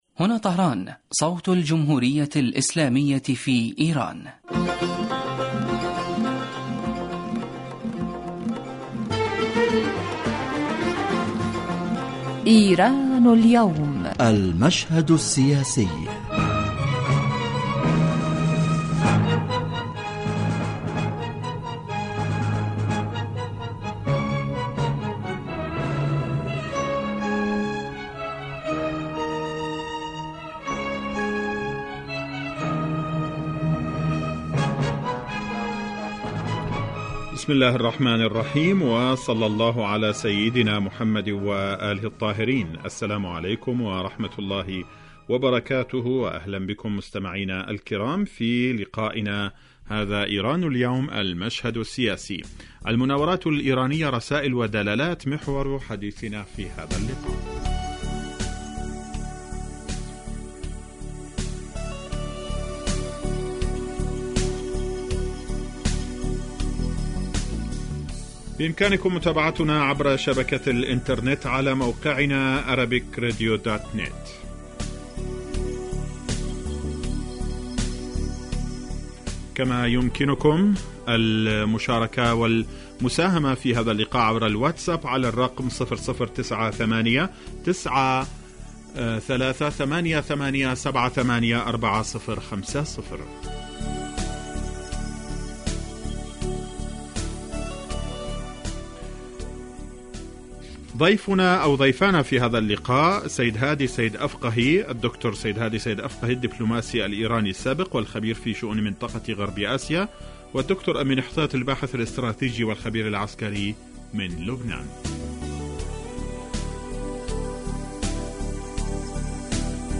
يتناول هذا البرنامج كما هو واضح من تسميته آخر القضايا والأحداث الإيرانية ويختص كل أسبوع بموضوع من أهم موضوعات الساعة في ايران وتأثيره على الساحة الإقليمية ويتطرق إليه ضيف البرنامج في الاستوديو كما يطرح نفس الموضوع للمناقشة وتبادل النظر على خبير آخر يتم استقباله على الهاتف